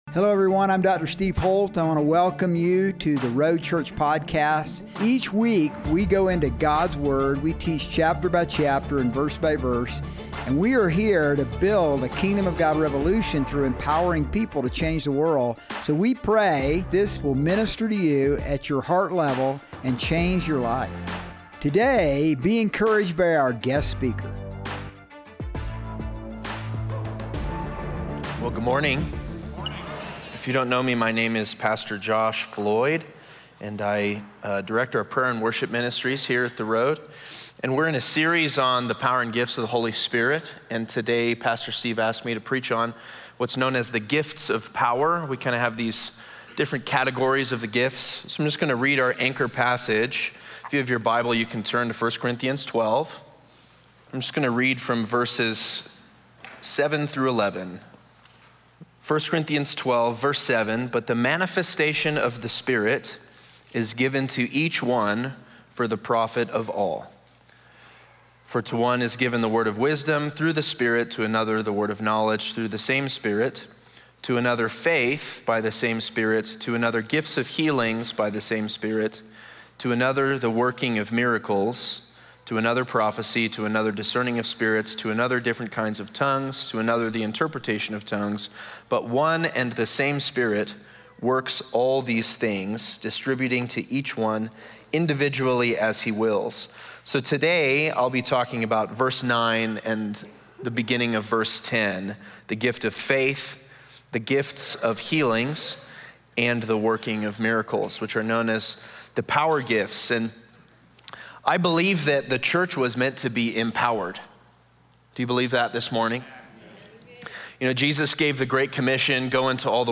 Sermons | The Road Church